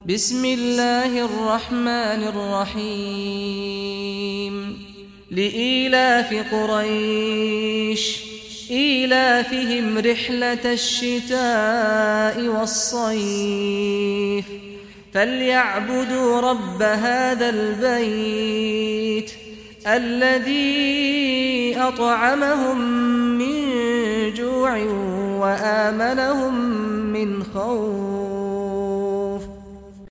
Surah Quraysh Recitation by Sheikh Saad Ghamdi
Surah Quraysh, listen or play online mp3 tilawat / recitation in Arabic in the beautiful voice of Sheikh Saad al Ghamdi.